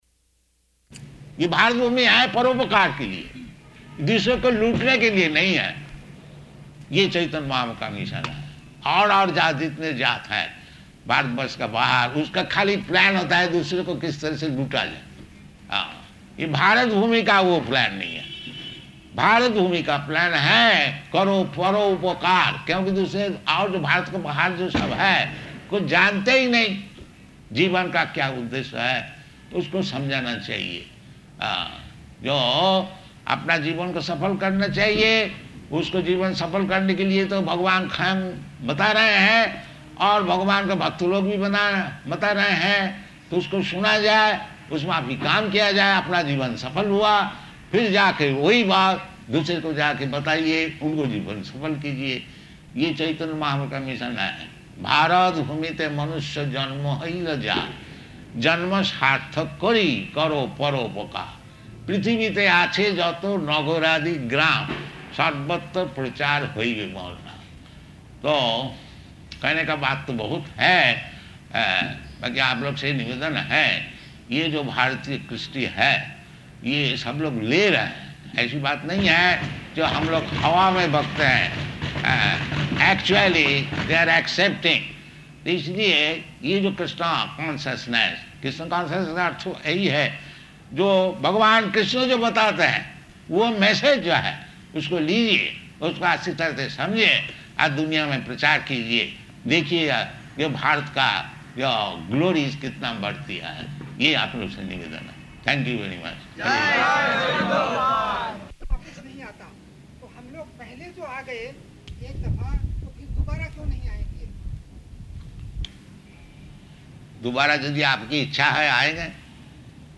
Lecture [Hindi and English--final proofreading pending]
Type: Lectures and Addresses
Location: Bombay